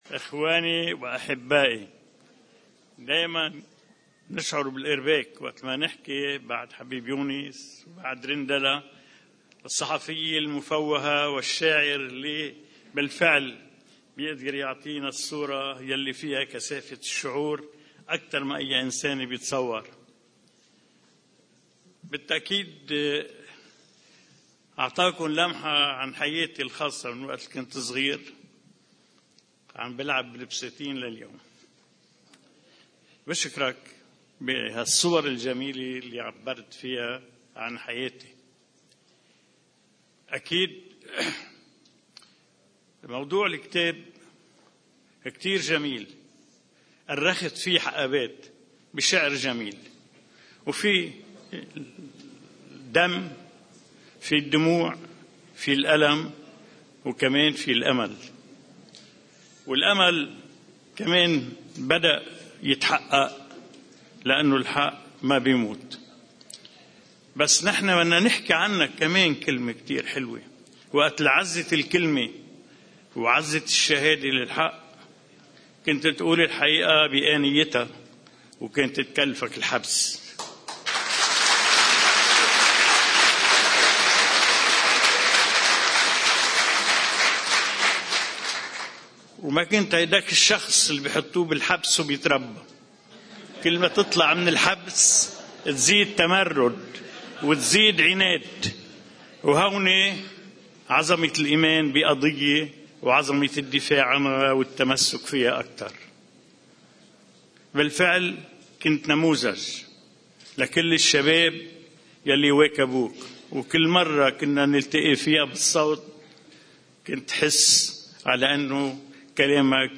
بالصوت والفيديو عن الصورة اللي تنبأ لها العماد عون عام 2014 وأعلنها في توقيع كتاب “وحدك كتار” (Audio+Video)